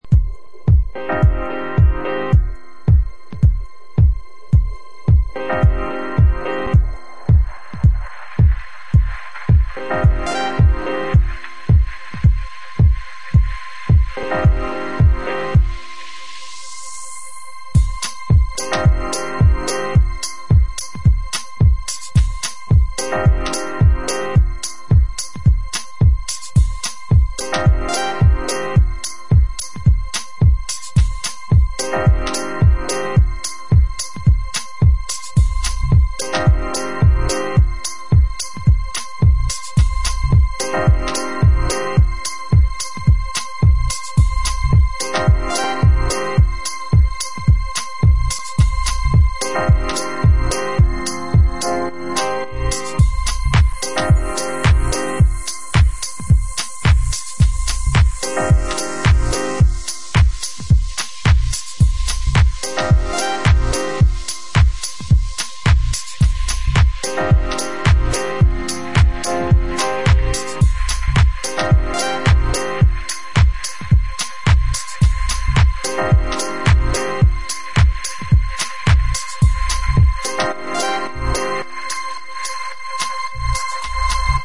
slowburning remix
soulful homage to the slow side of house music
House